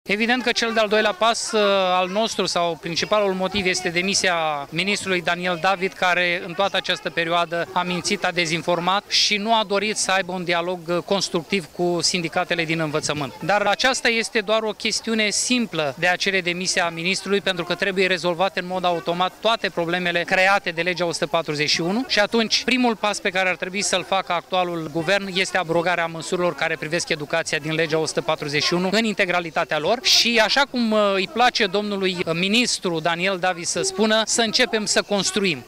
1-sept-ora-15-lider-sindicat.mp3